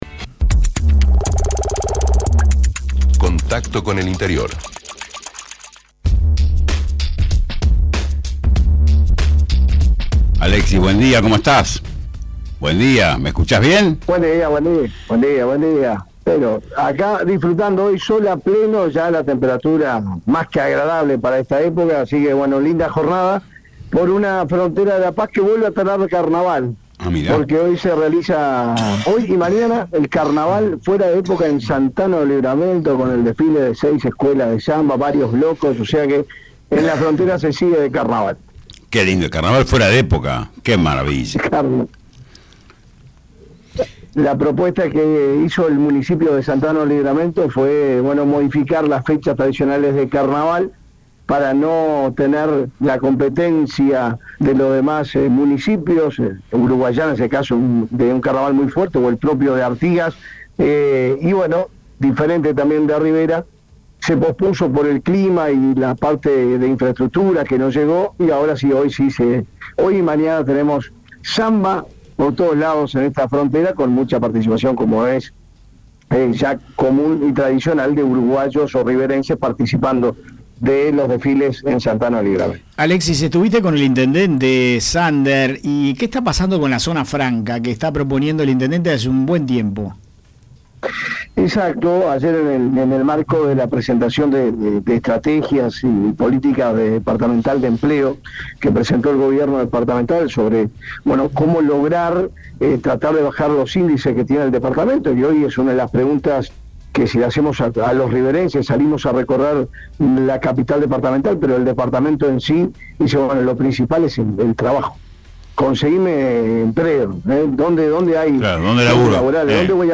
presenta manifestaciones del intendente del departamento, Richard Sander, quien brindó información sobre el proceso de inversión para la primera etapa de las 40 hectáreas de la zona franca, en ese punto del país.